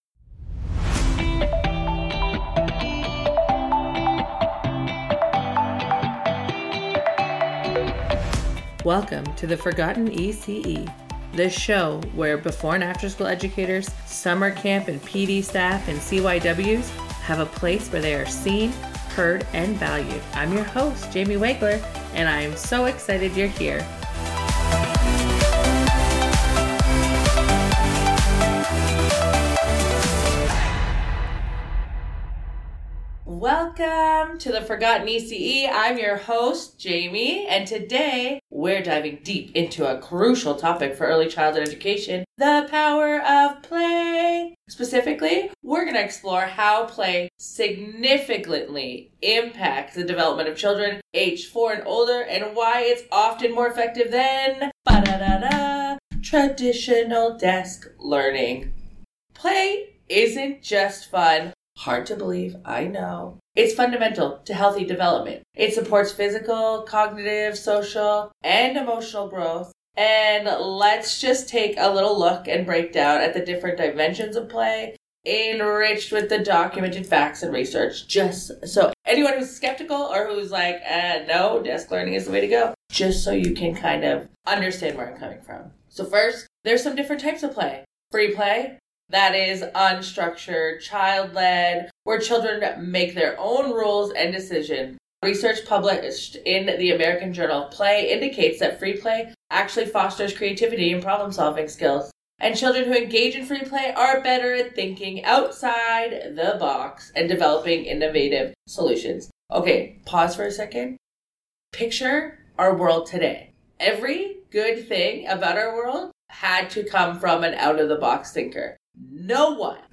Tune in for a thoughtful conversation on how we can better support our youngest learners during these crucial hours.